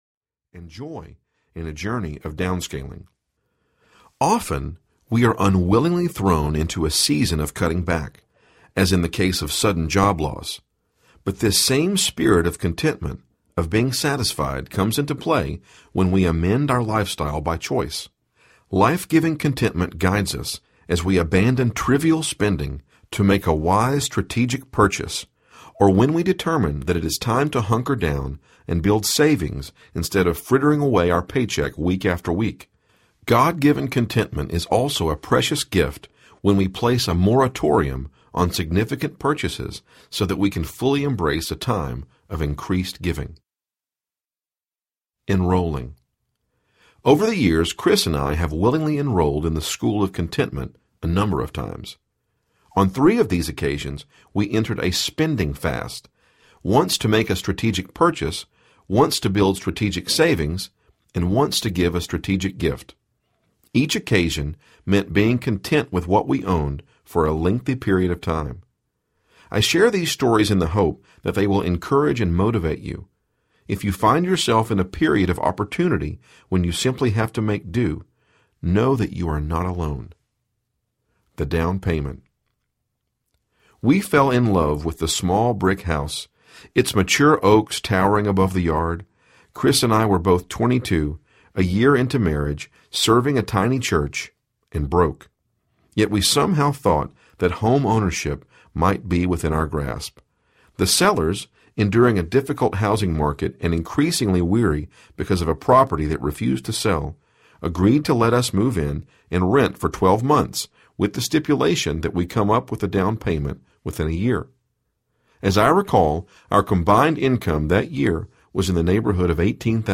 Satisfied Audiobook
4.4 Hrs. – Unabridged